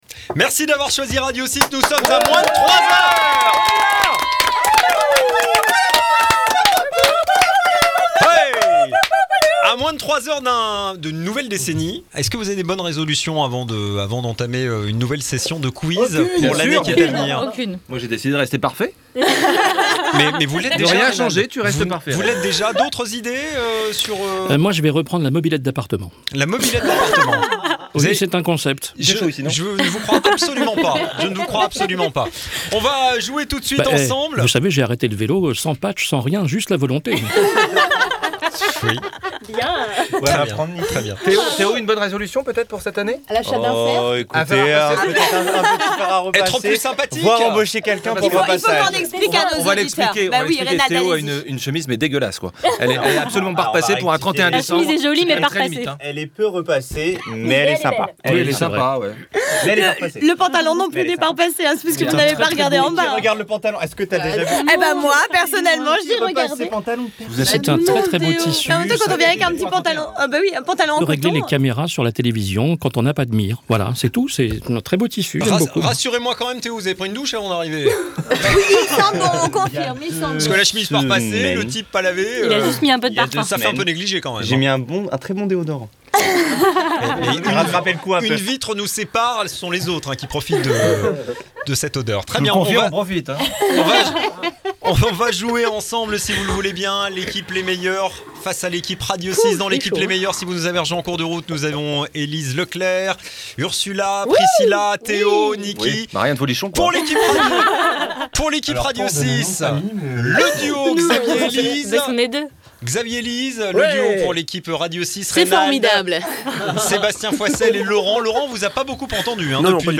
Quizz, fous rires, bonne humeur...Revivez les meilleurs moments du réveillon RADIO 6 avec le premier REPLAY de 2020 !